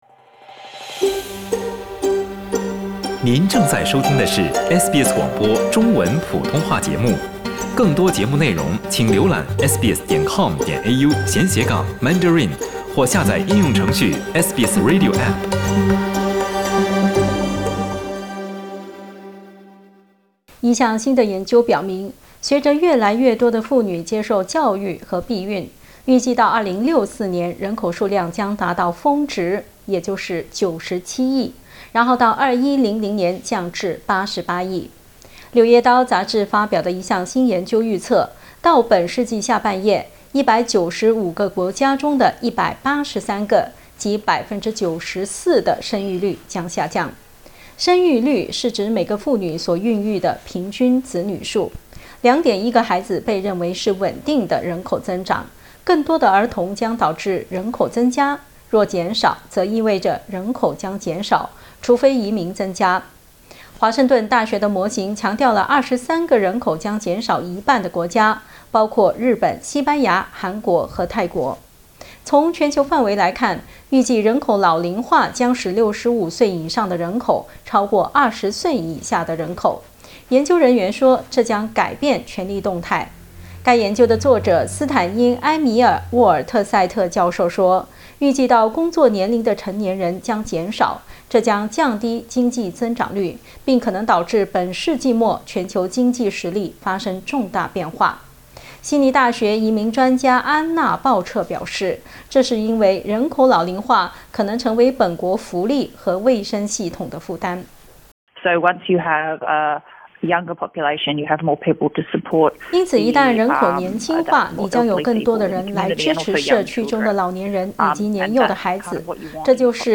一項新的研究表明，隨著越來越多的婦女接受教育，以及實施避孕，預計到2064年人口數量將達到峰值，達到97億，到2100年降至88億。 《柳葉刀》雜志髮表的一項新研究預測，到本世紀下半葉，195個國家中的183個（即94％）的生育率將下降。 點擊圖片收聽詳細報道。